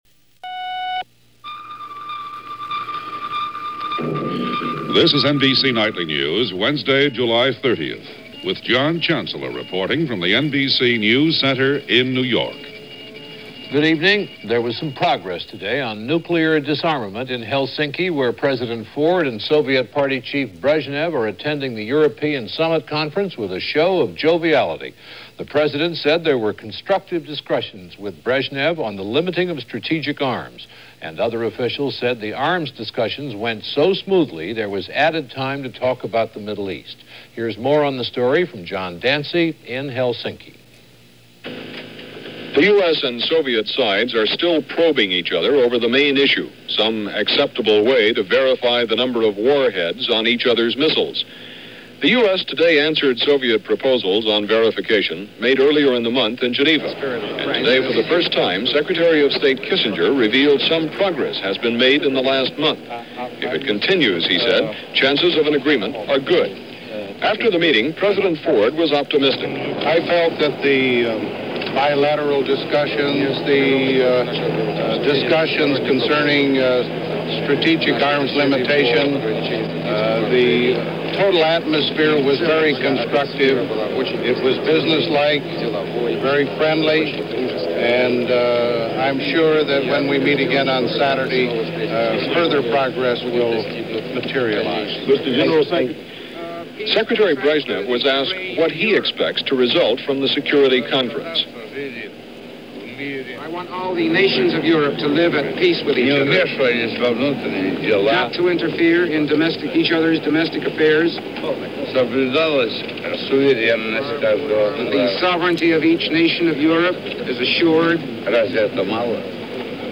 – NBC Nightly News – July 30, 1975 – Gordon Skene Sound Collection –